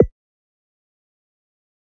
click-short-confirm.ogg